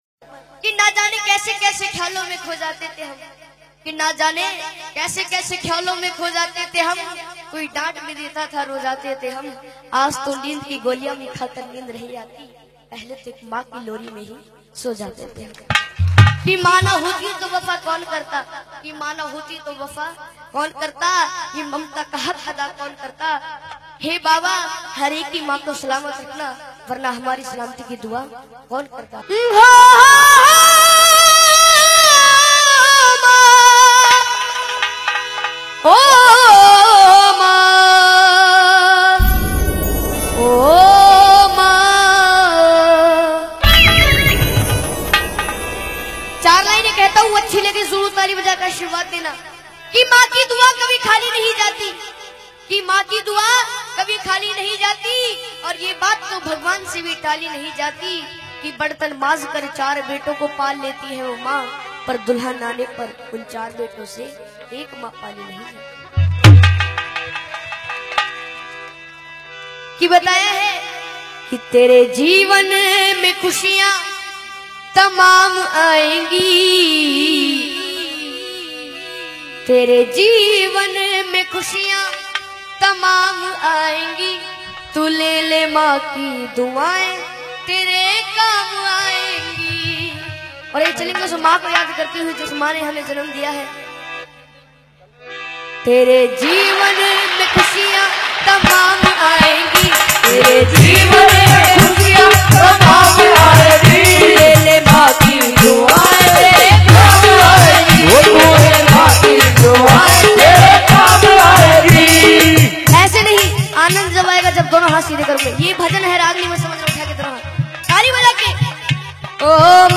Haryanvi Ragni Songs